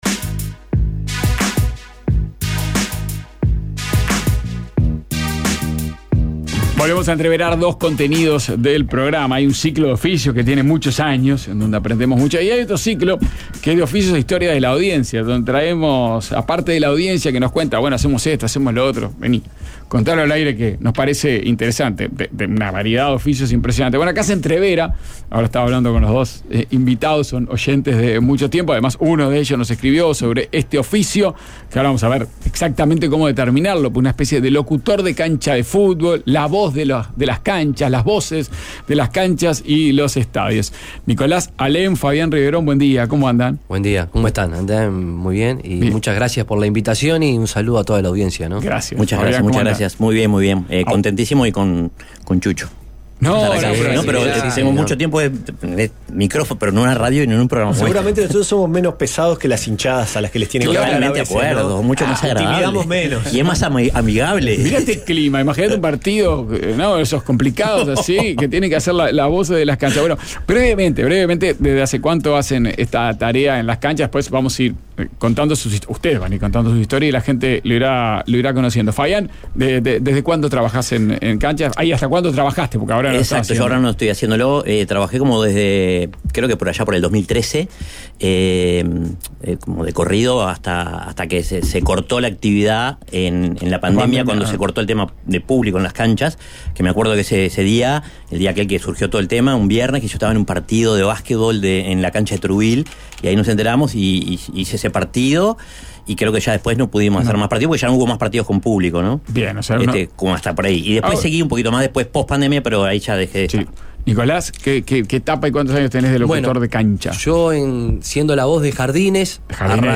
Tres guardavidas que acaban de terminar una larga temporada nos hablan sobre su oficio, los descuidos en la playa y nos explican por qué saber nadar no tiene nada que ver con estar seguros en el mar.